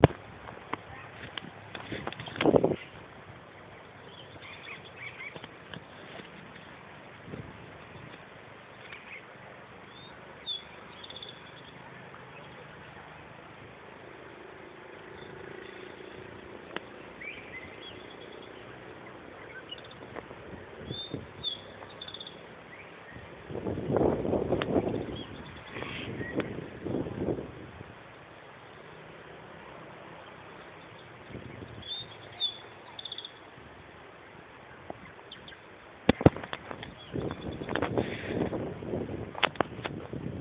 Huayco Tinamou (Rhynchotus maculicollis)
Province / Department: Catamarca
Location or protected area: Las Juntas
Condition: Wild
Certainty: Recorded vocal